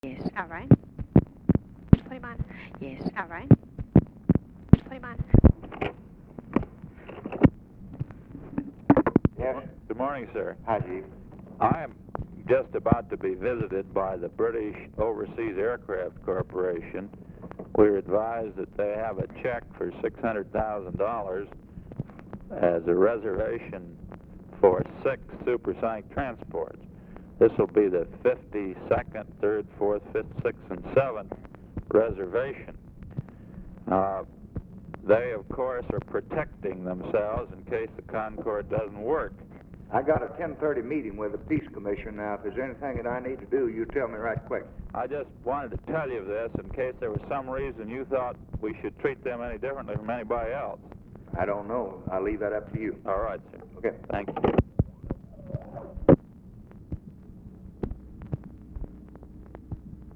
Conversation with NAJEEB HALABY, January 23, 1964
Secret White House Tapes